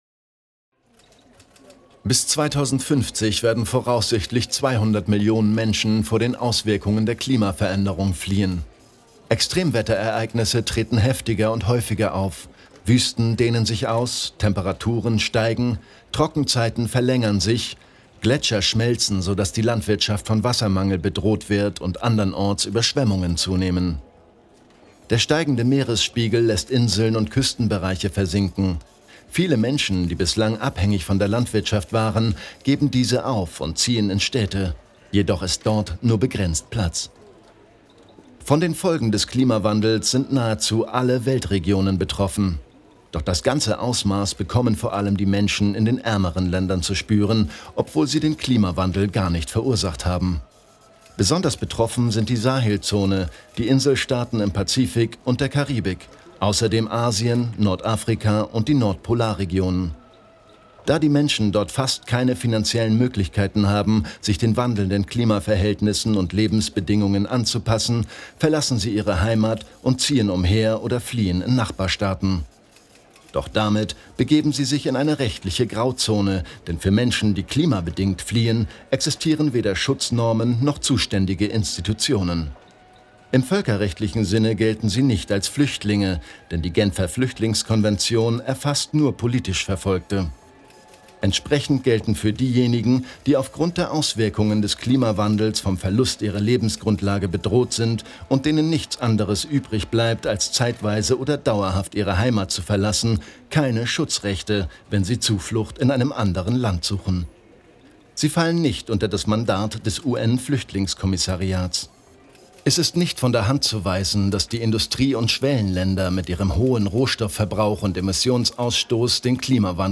Zivilvertreter erzählen: